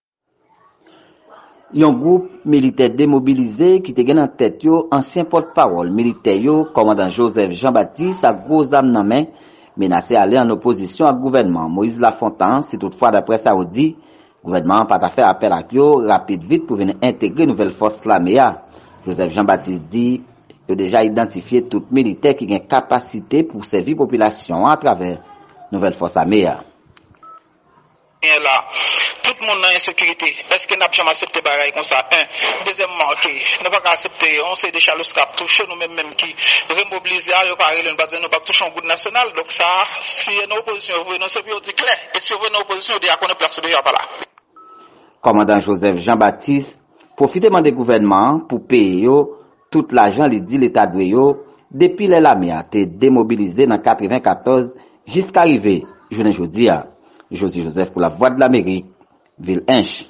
Deklarasyon